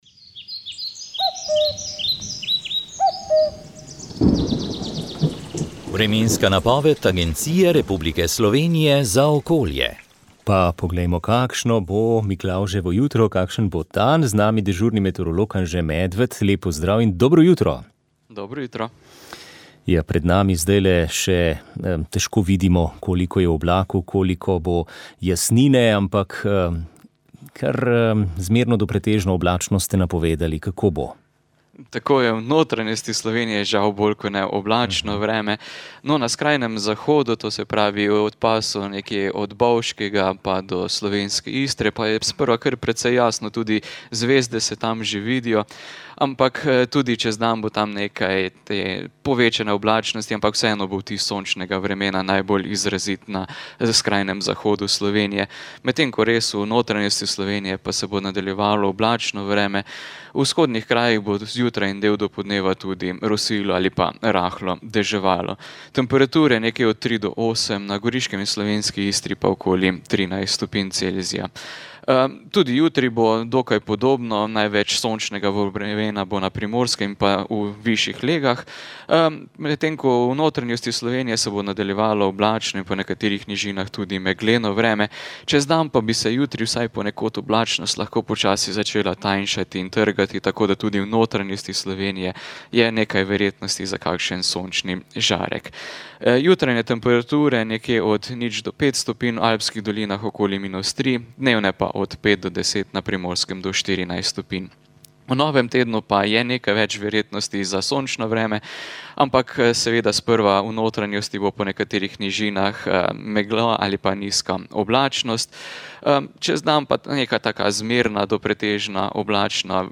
Vremenska napoved